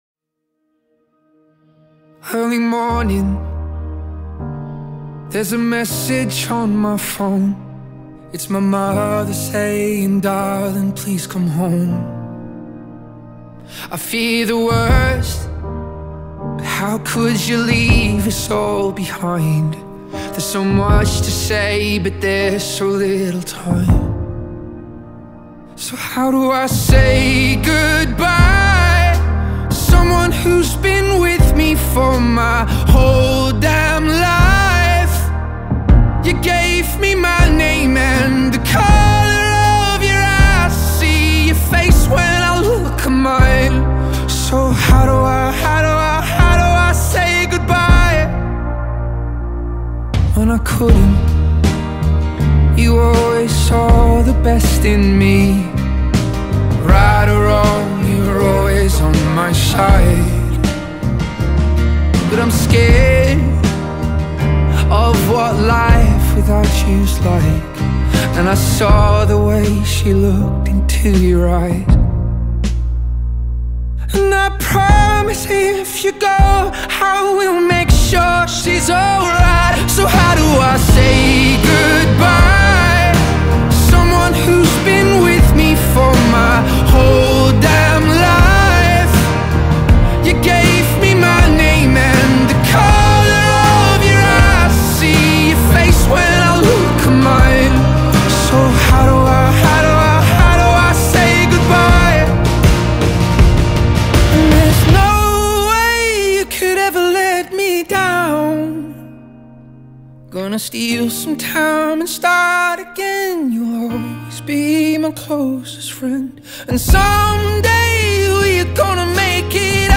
یک آهنگ فوق العاده احساسی